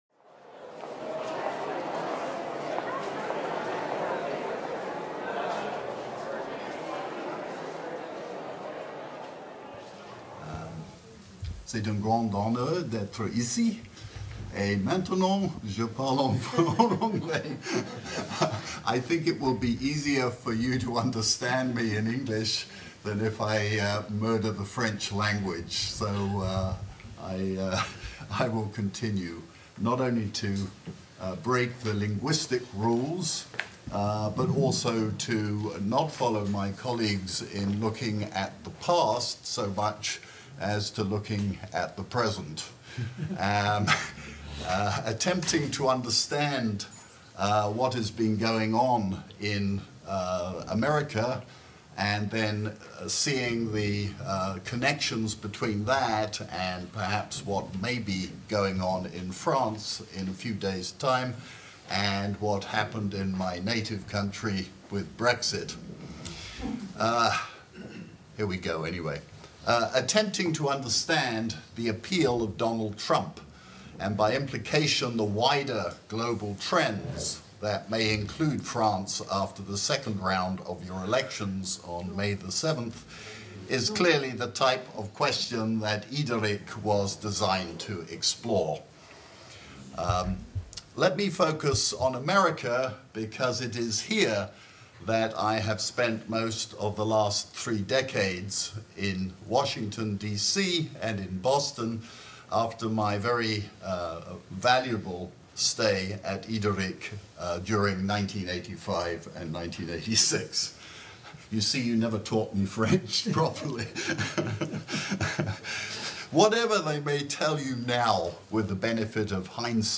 Table ronde : les recherches sur les relations interethniques dans leur contexte théorique et institutionnel : héritages, passerelles, ruptures, discontinuités